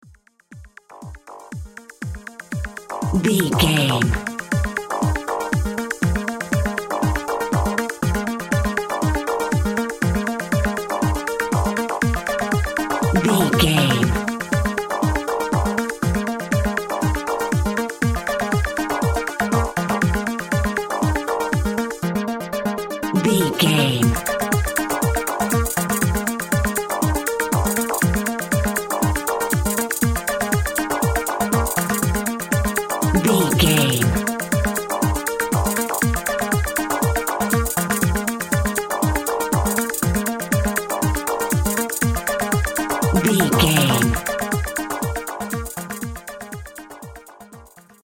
Euro Club Dance Music.
Mixolydian
B♭
groovy
uplifting
driving
energetic
repetitive
drums
synthesiser
drum machine
euro house
synth lead
synth bass